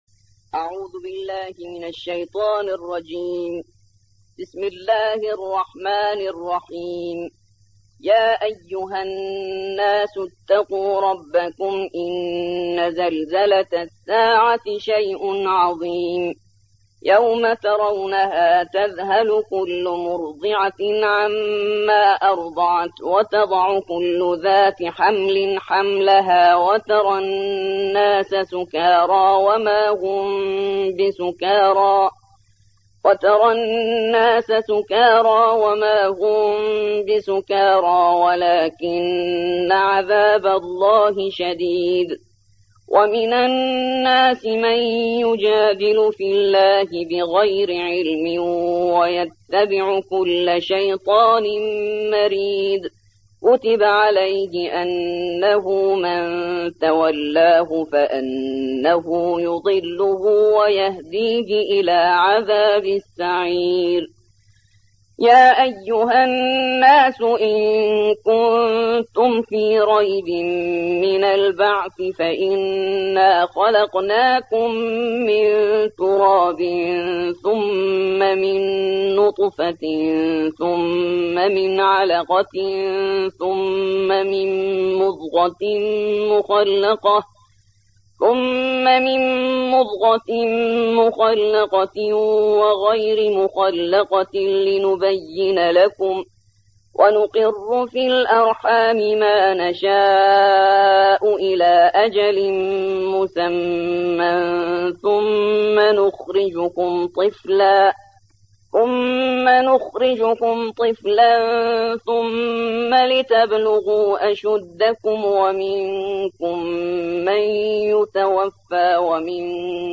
22. سورة الحج / القارئ